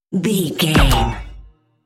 Sci fi gun shot whoosh fast
Sound Effects
Fast
futuristic
whoosh